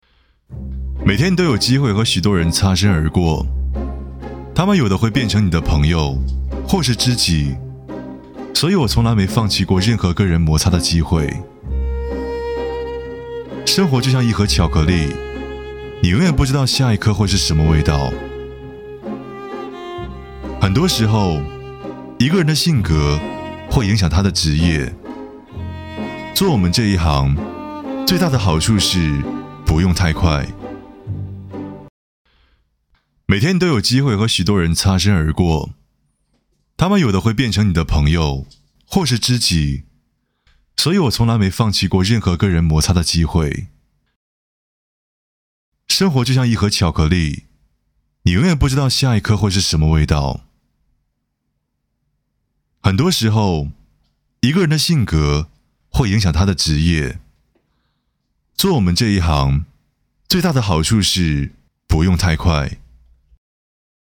男678-【台湾腔】生活这件事-王家卫风格 广告
男678--台湾腔-生活这件事-王家卫风格-广告.mp3